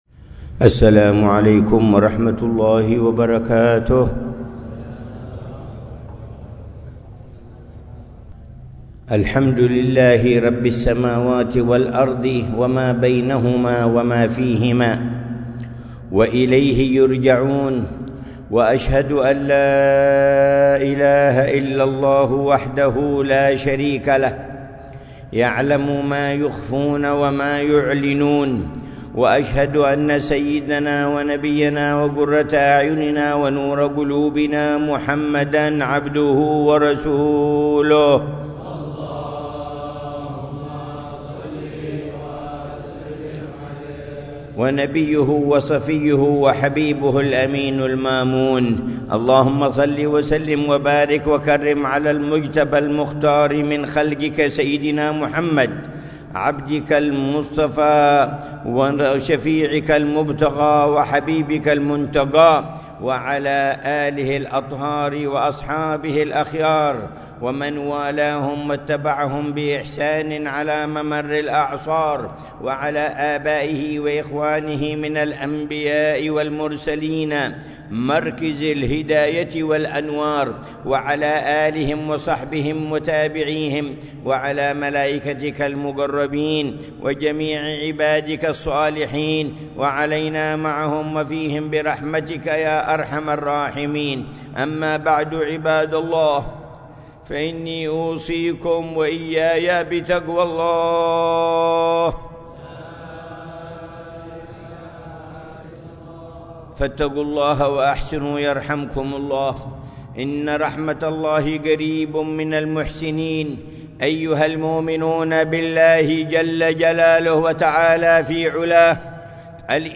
خطبة الجمعة للعلامة الحبيب عمر بن محمد بن حفيظ في مسجد الجامع في حوطة الإمام أحمد بن زين الحبشي، في مديرية شبام، وادي حضرموت، 26 جمادى الآخرة 1446